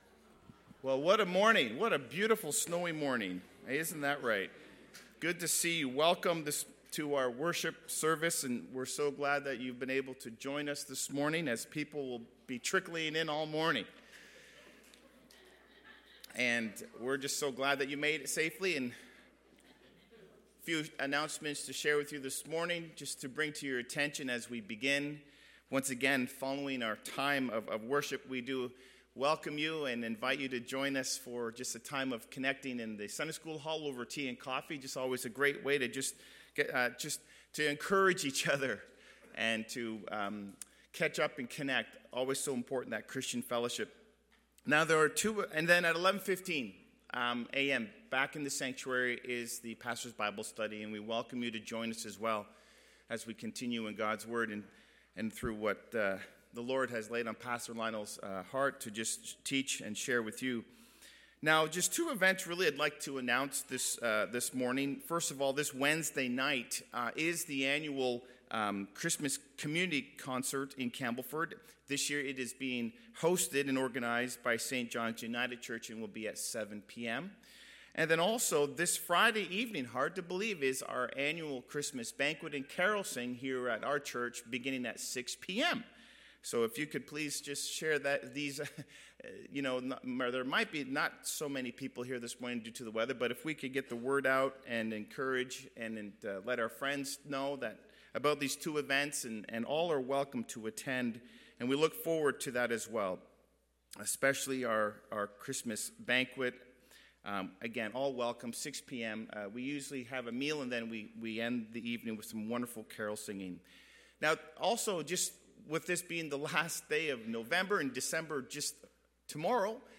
Video Sermons - Campbellford Baptist Church Inc.